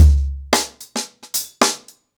HarlemBrother-110BPM.23.wav